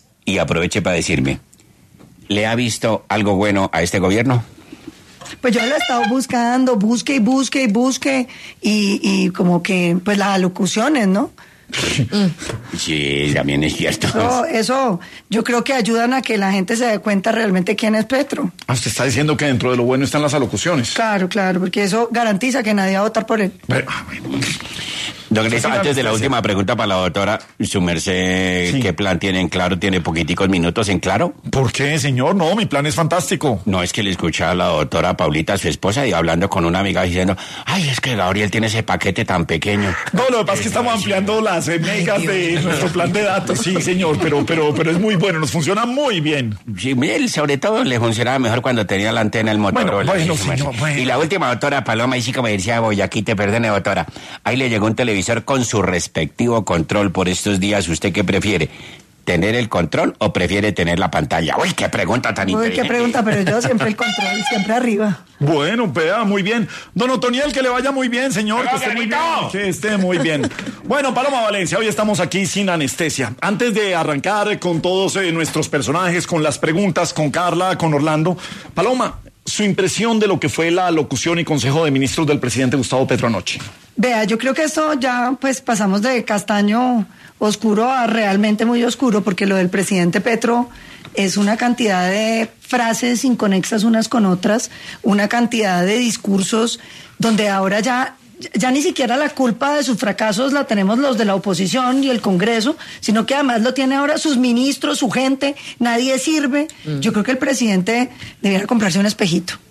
La senadora del Centro Democrático, Paloma Valencia, estuvo en ‘Sin Anestesia’ para hablar de la última alocución de Gustavo Petro.
Con tono sarcástico, Valencia mencionó que, tras escuchar las alocuciones presidenciales, no ha hallado méritos claros, pero que estas intervenciones sin duda pueden tener algo positivo para la oposición.